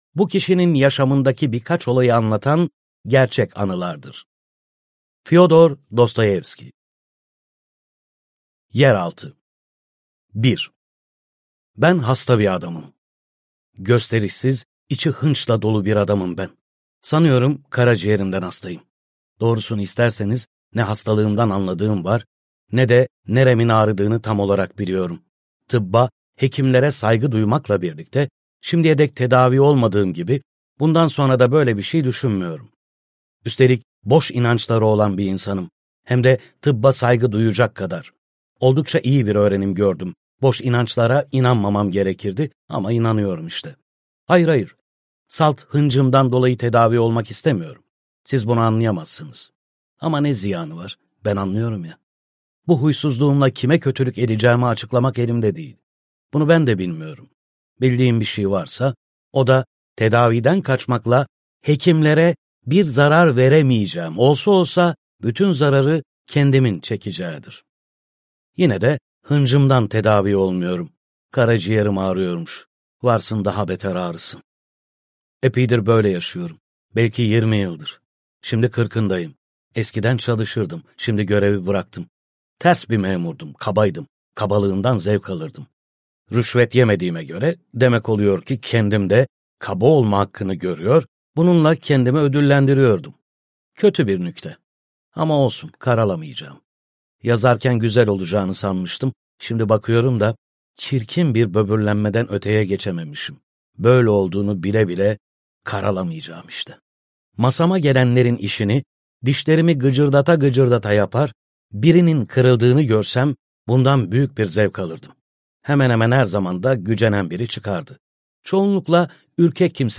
Yeraltından Notlar - Seslenen Kitap